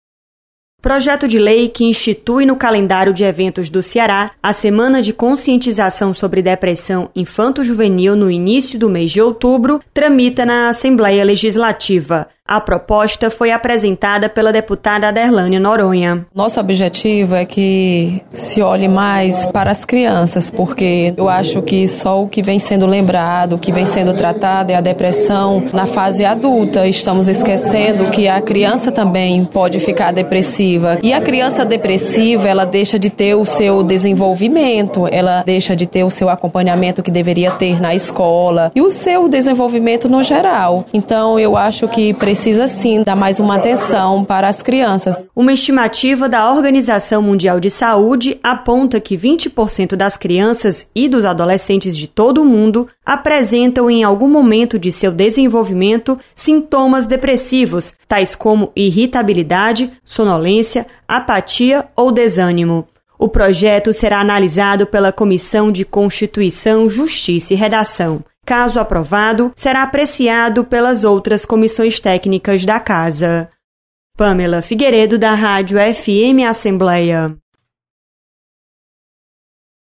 Deputada quer instituir semana de conscientização sobre depressão em crianças e adolescentes. Repórter